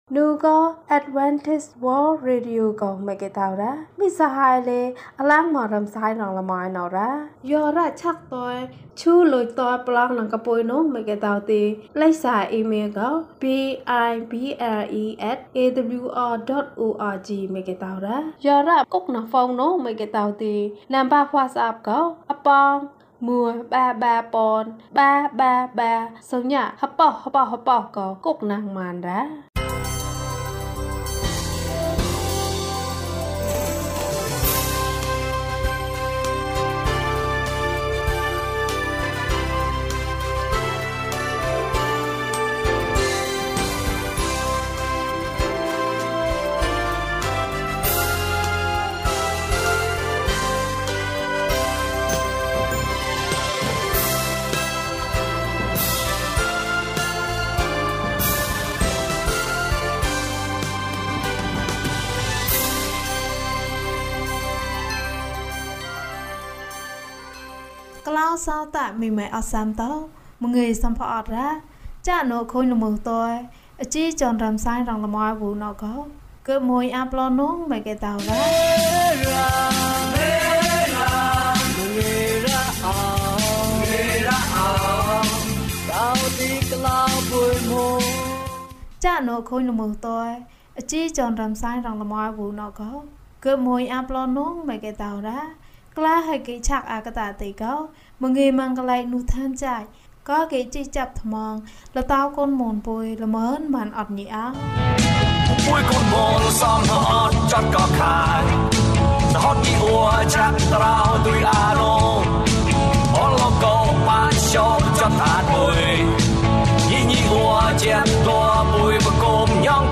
ယေရှုခရစ်သည် ကျွန်ုပ်၏အသက်ကို ကယ်တင်ပါ။၀၁ ကျန်းမာခြင်းအကြောင်းအရာ။ ဓမ္မသီချင်း။ တရားဒေသနာ။